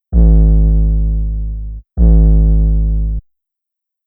808s
pbs - dp III [ 808 ].wav